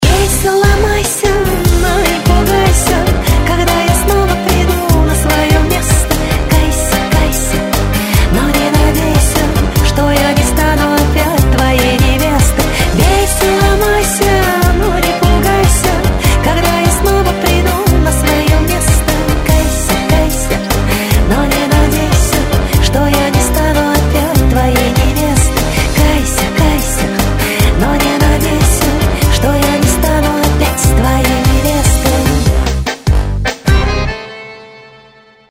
Танцевальные рингтоны
Поп